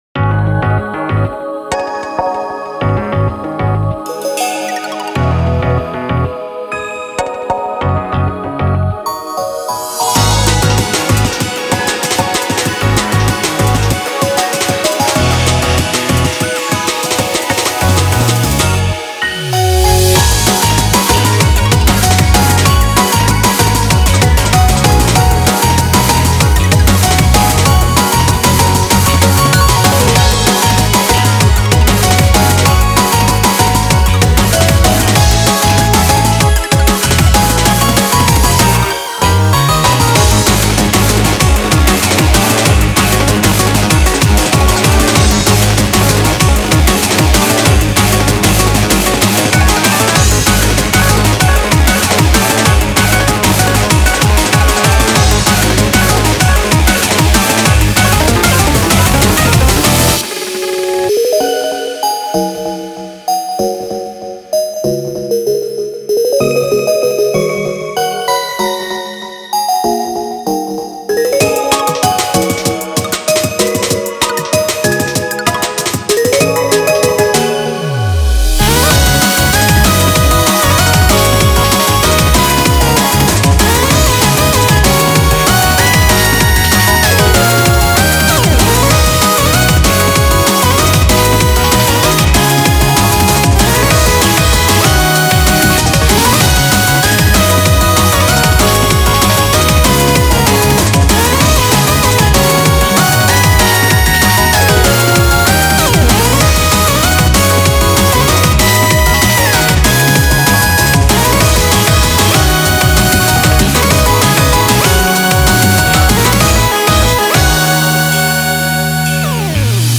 BPM96-192
Audio QualityPerfect (High Quality)